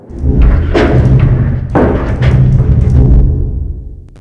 tanksfilled.wav